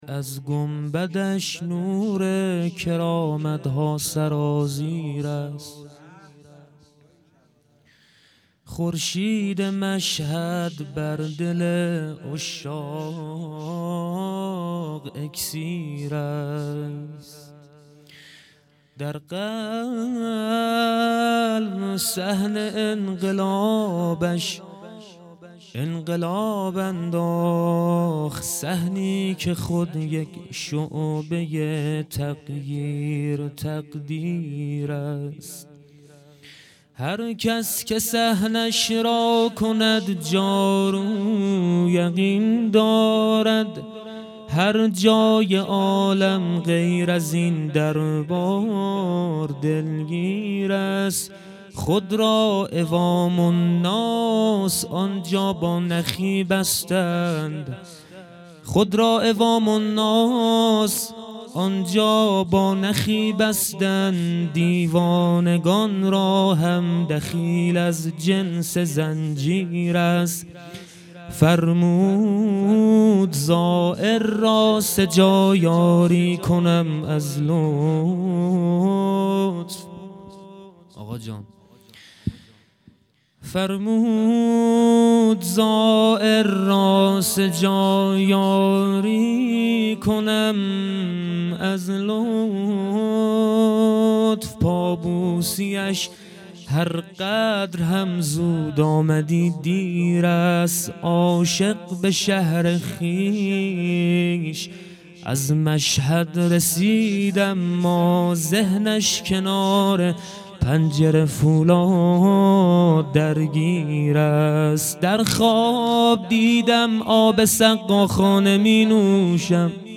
مدح بسیار زیبا امام رضا"ع"
ولادت امام رضا"ع"۹۹